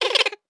Laugh_v4_wav.wav